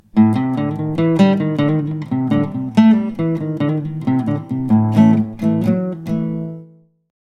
Acoustic Guitar Mic Example
One mic about 6″ above the 12th fret pointing down, the other about 15″ from the sound hole.